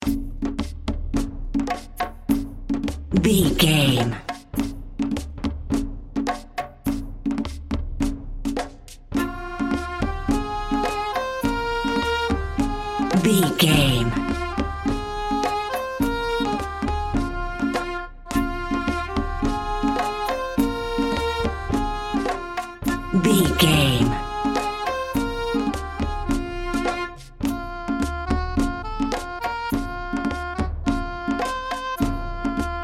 Aeolian/Minor
folk music
world beat
Synth Pads
strings